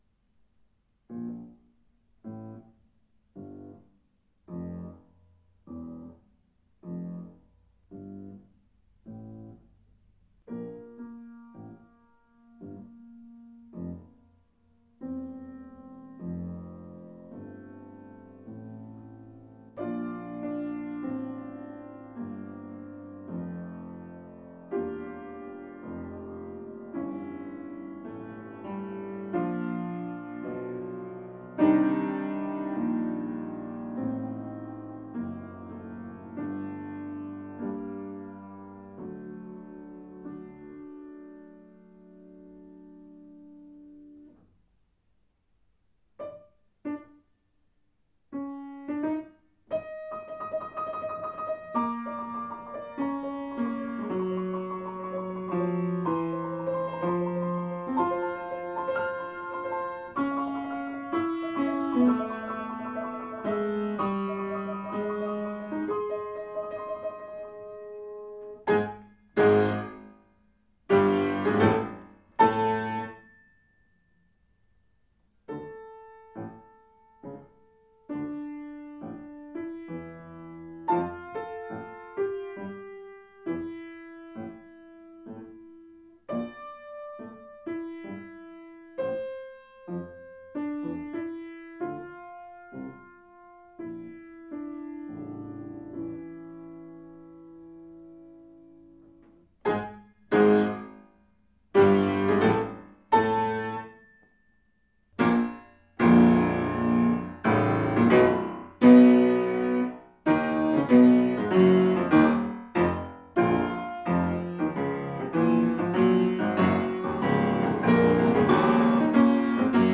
piano transcription